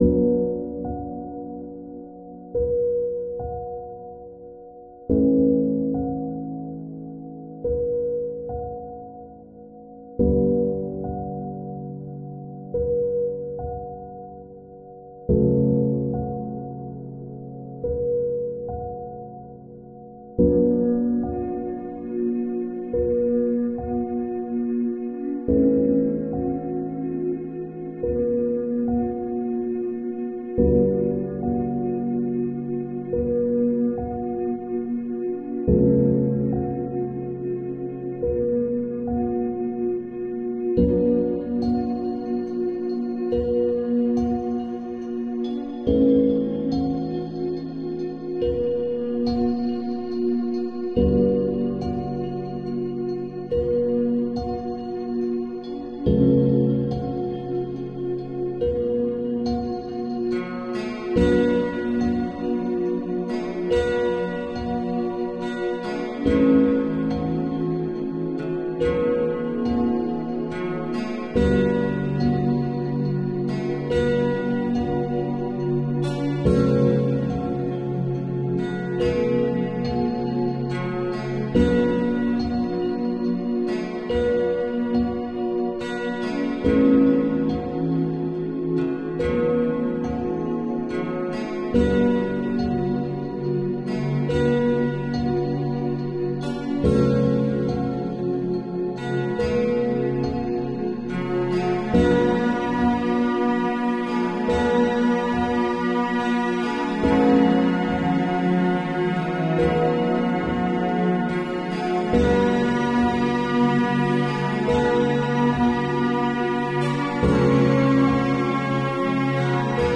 SpaceX's 2nd Starship Launch Test Sound Effects Free Download
SpaceX's 2nd Starship launch test